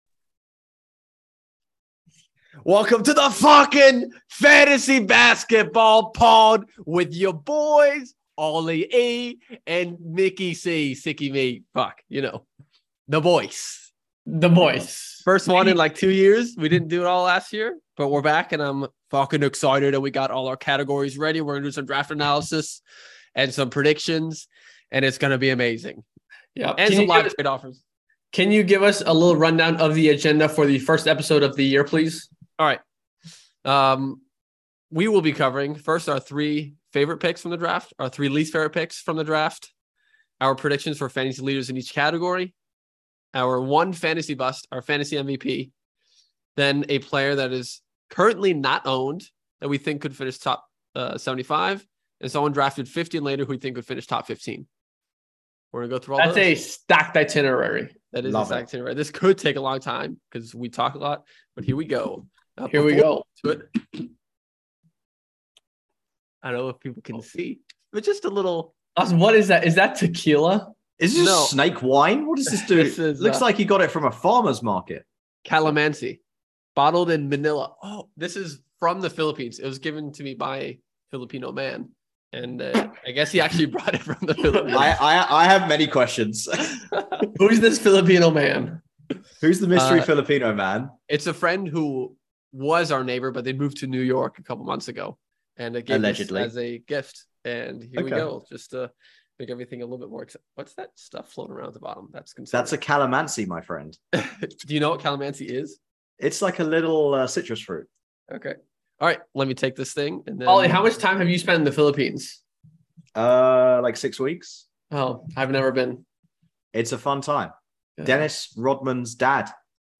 RAW and unfiltered fantasy basketball talk by 3 regular dudes who are way too obsessed with fantasy basketball.